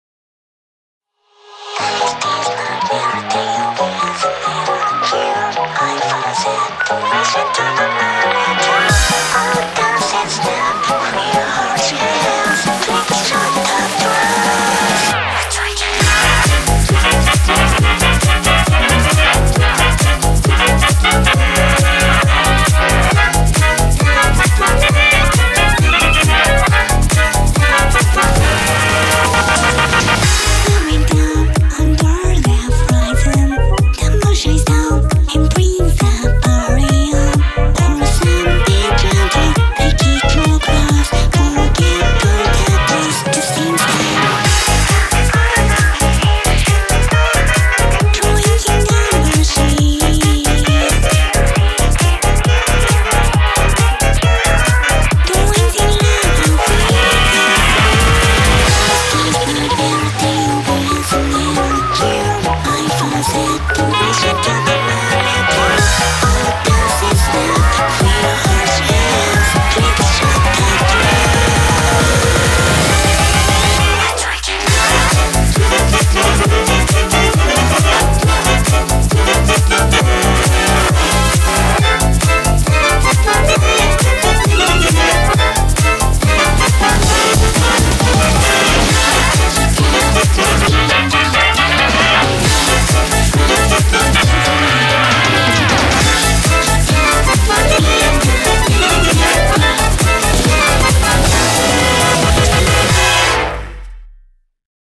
BPM135
Audio QualityPerfect (High Quality)
electro swing